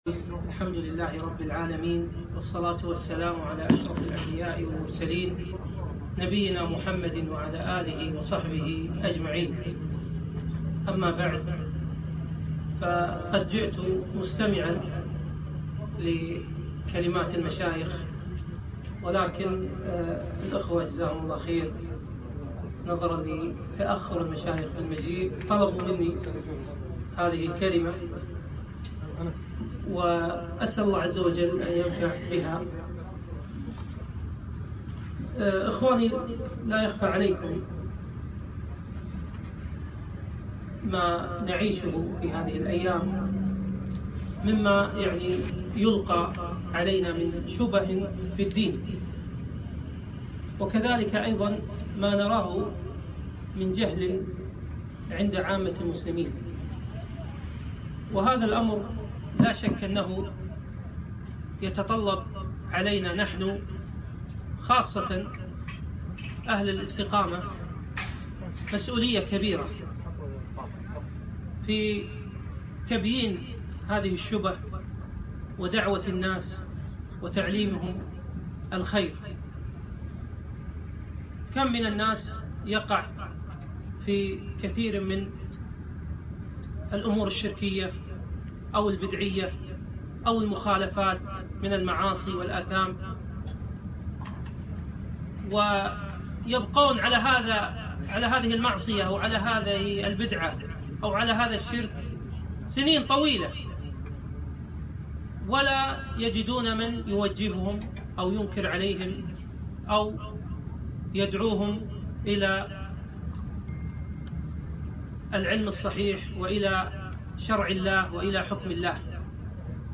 كلمة في مخيم زاد المسلم الدعوي